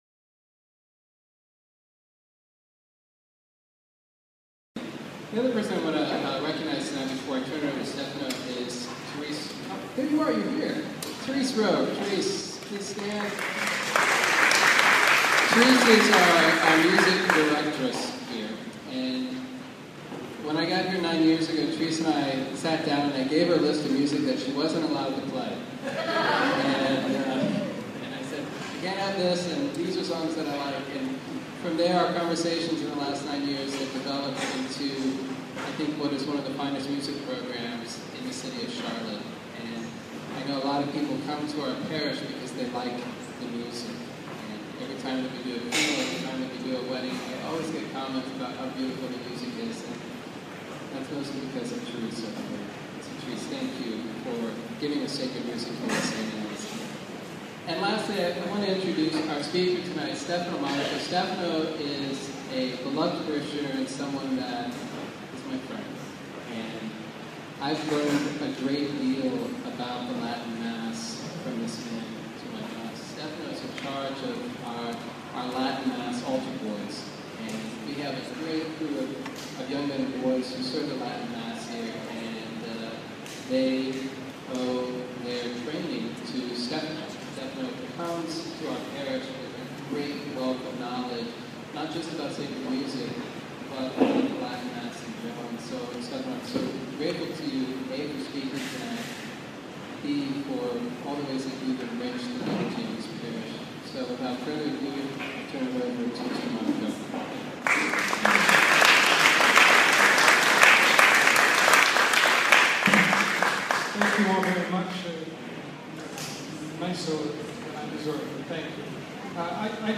Sacred Music Class